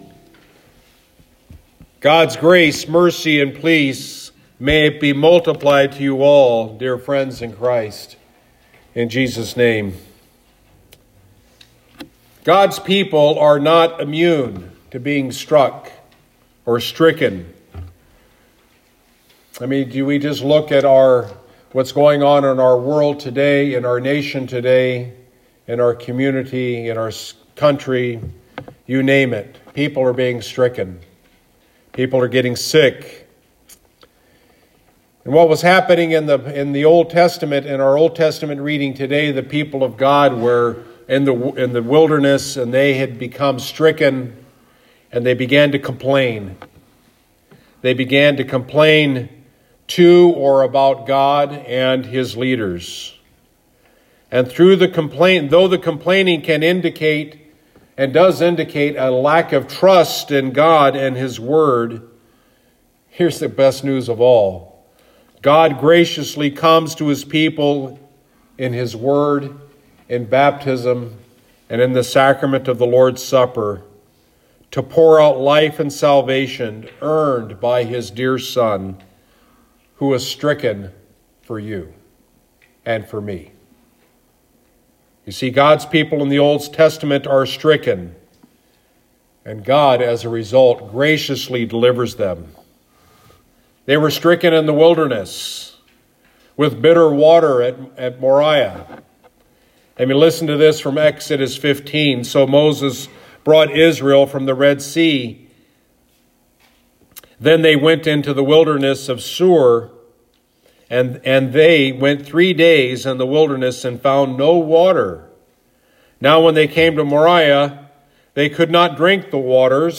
Sunday Sermon — “Stricken for You”